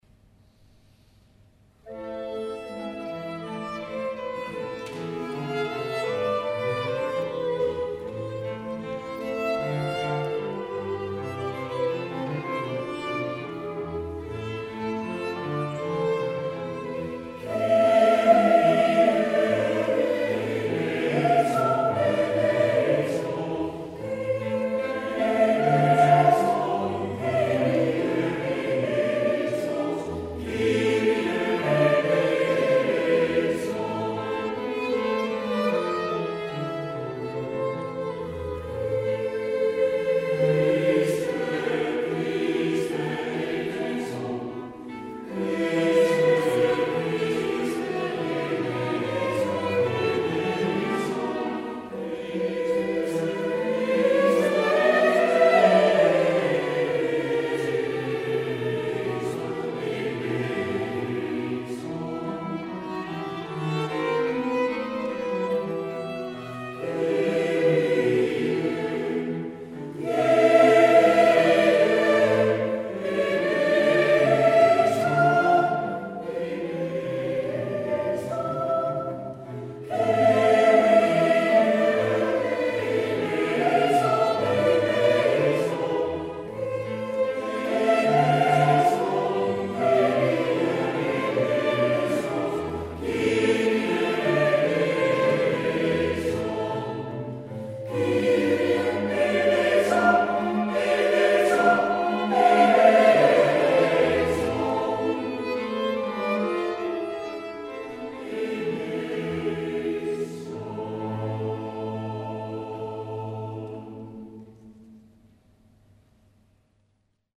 Chorus ad Hoc - Audio
live opname cantatedienst 13 maart 2011:
Op deze pagina zijn diverse live opnamen te horen,
...geen studio kwaliteit...